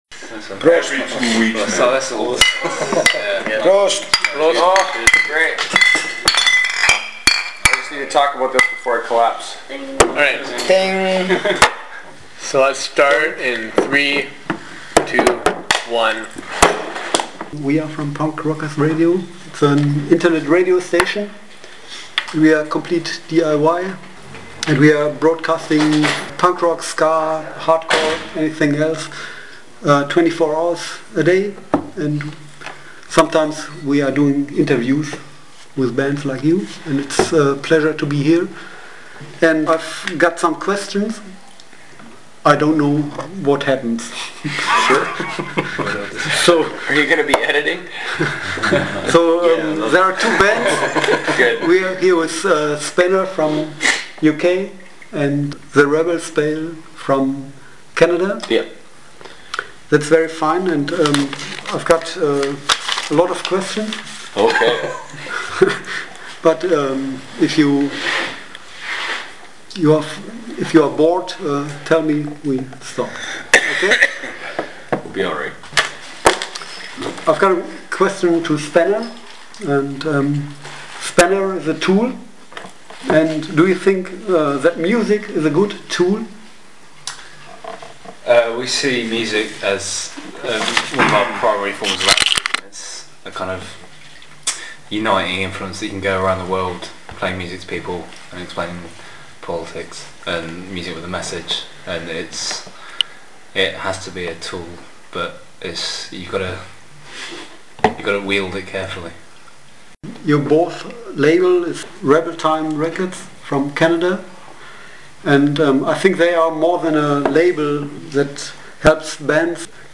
Letzte Episode Spanner vs. The Rebel Spell 22. November 2013 Nächste Episode download Beschreibung Teilen Abonnieren Im Herbst 2013 waren die Bands Spanner und The Rebel Spell auch in Deutschland auf Tour. Beim Auftritt im Linken Zentrum in Düsseldorf am 23. November konnte ich beide Bands nach dem Auftritt gemeinsam interviewen.